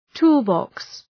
Προφορά
{‘tu:l,bɒks}